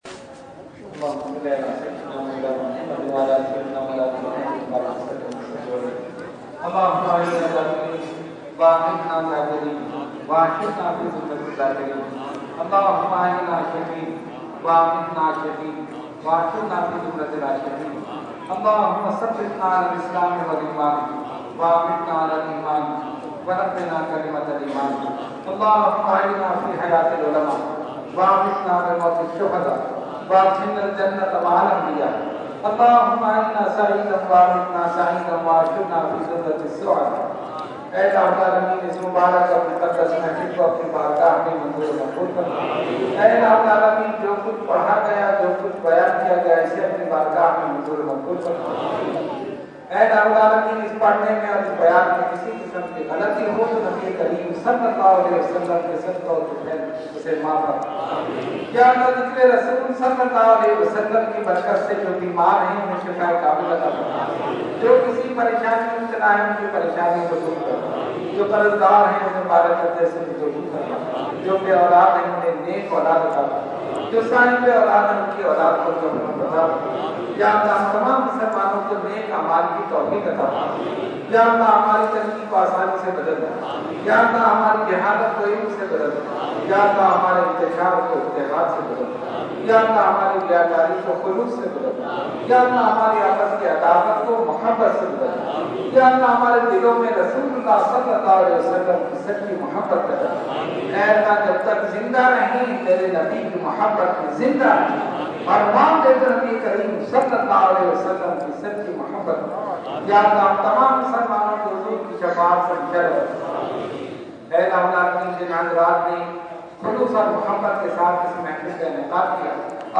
Dua – Eid Milad un Nabi Liaqatabad 2014 – Dargah Alia Ashrafia Karachi Pakistan